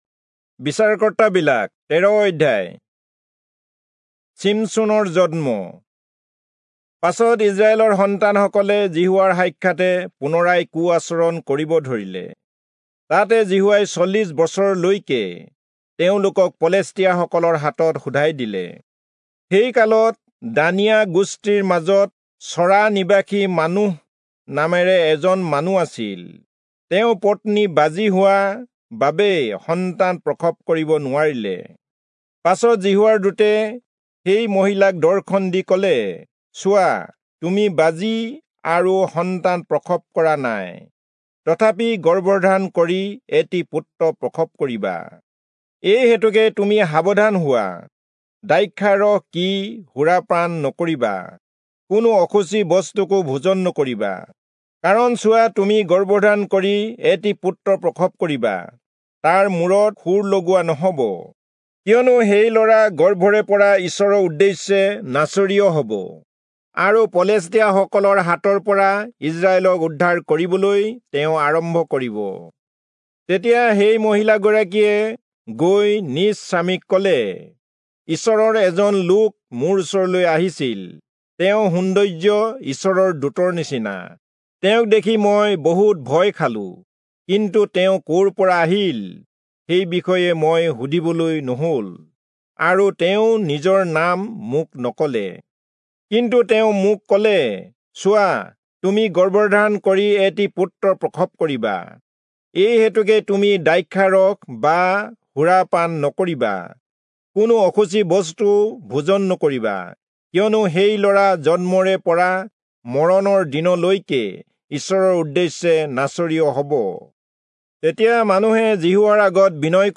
Assamese Audio Bible - Judges 7 in Ervml bible version